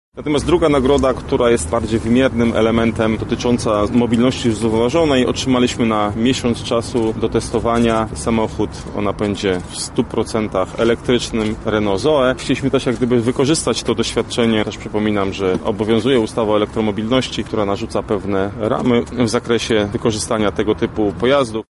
O wyróżnieniu w kategorii Mobilności mówi Artur Szymczyk, Zastępca Prezydenta Miasta Lublin do spraw Inwestycji i Rozwoju: